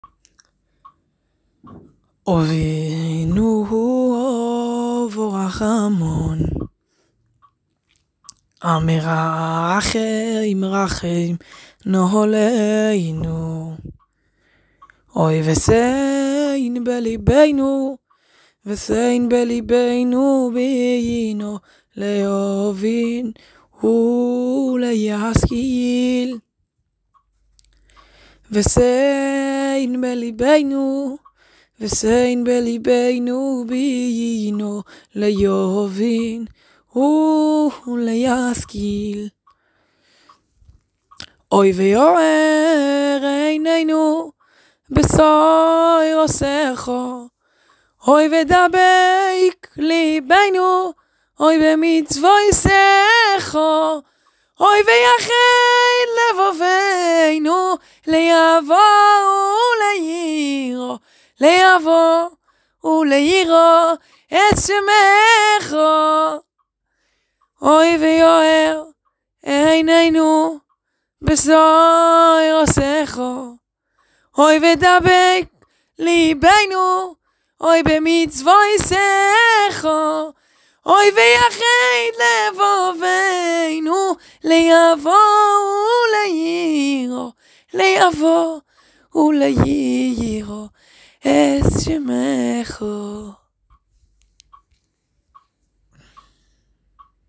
73 BPM
הערה קטנה: תנסה להלחין שיר שלא נמרח במילים (ששרים לאט)